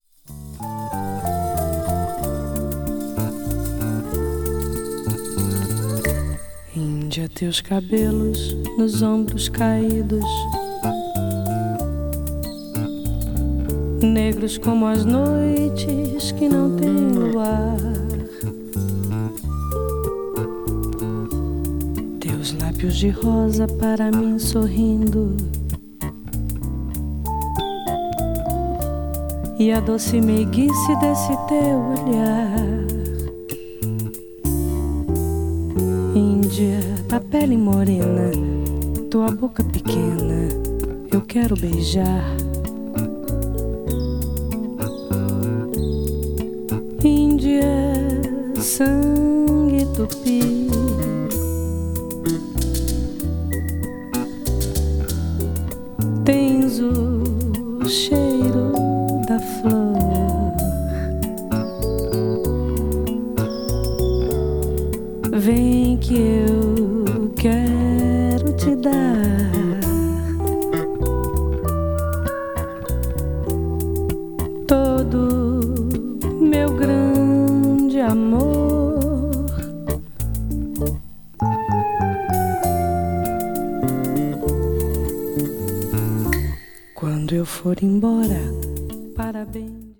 one of the leading female singers in the Brazilian MPB scene
an exotic slow number
brazil   mpb   south america   tropical   world music